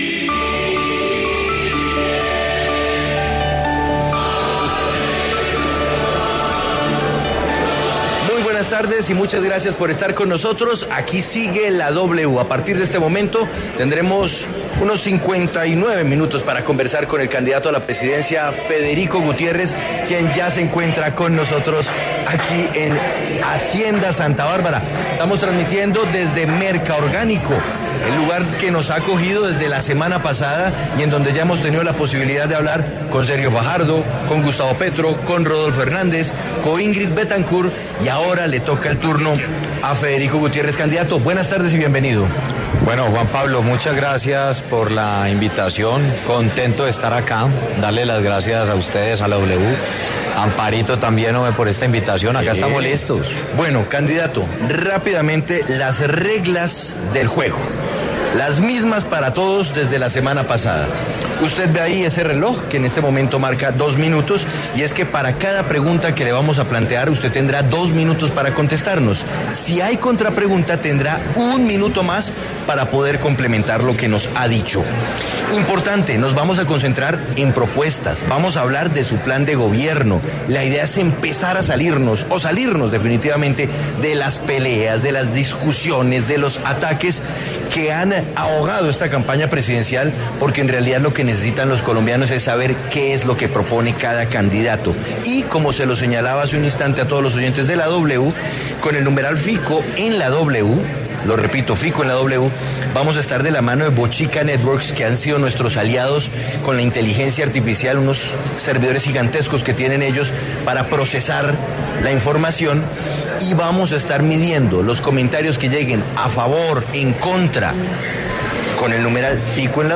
El candidato presidencial Federico Gutiérrez conversó en Sigue La W sobre sus propuestas y la forma en la que piensa llegar a segunda vuelta.